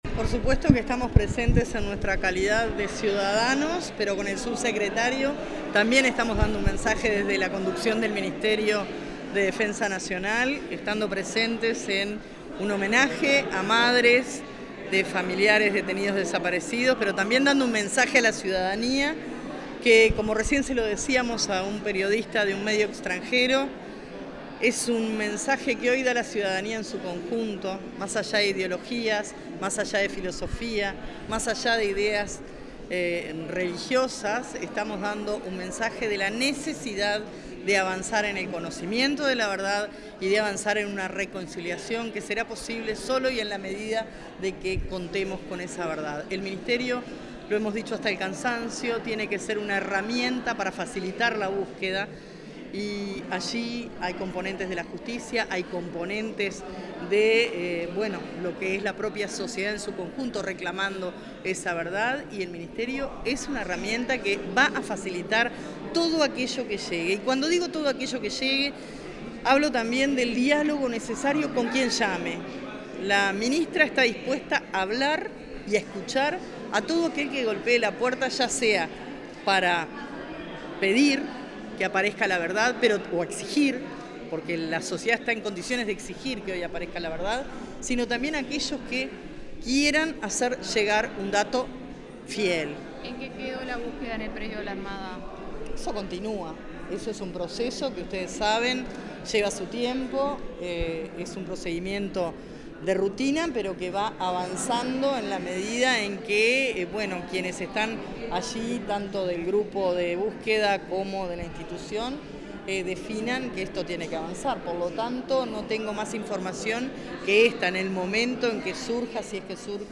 Declaraciones a la prensa de la ministra de Defensa Nacional, Sandra Lazo
La ministra de Defensa Nacional, Sandra Lazo, dialogó con la prensa después de participar en el acto de reconocimiento a las madres de los detenidos